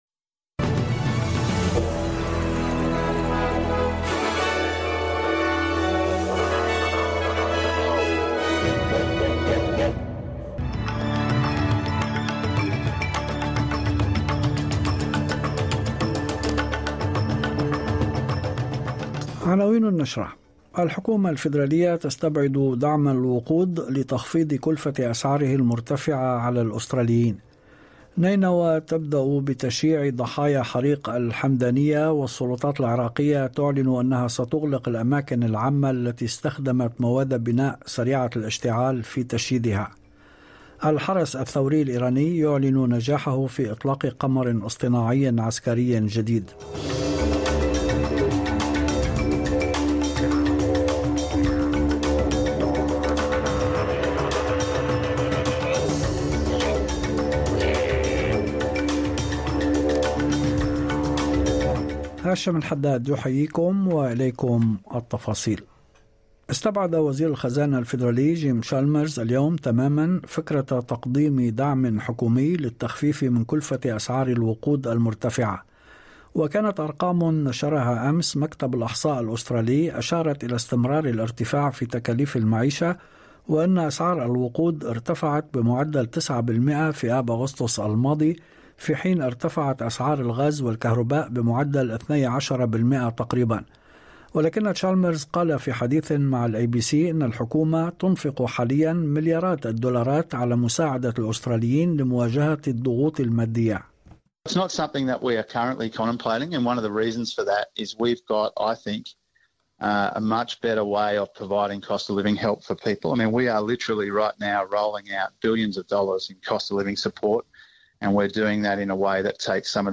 نشرة أخبار المساء 28/9/2023